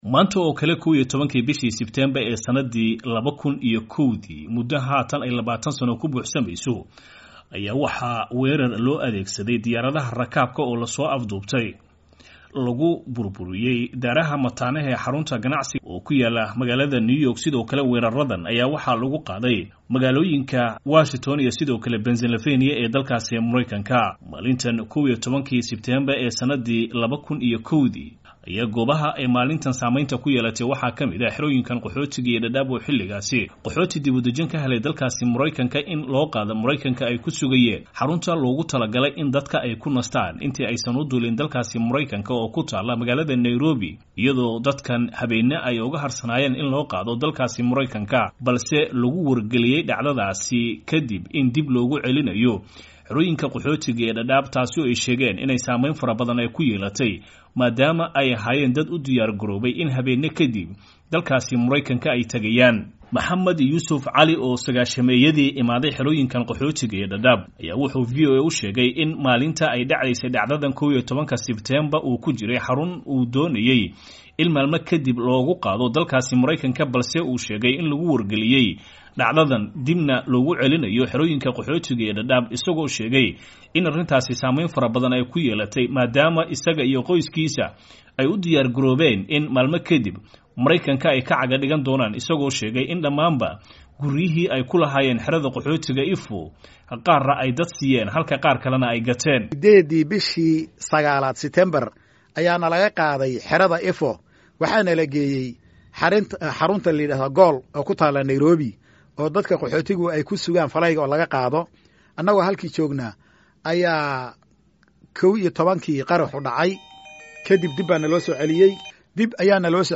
DADAAB —